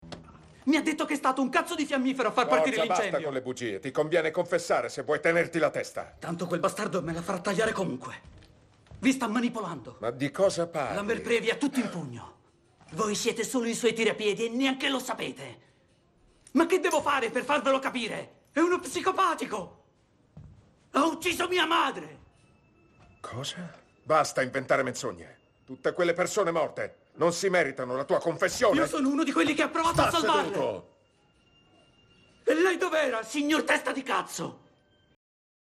nel telefilm "Destini in fiamme", in cui doppia Victor Meutelet.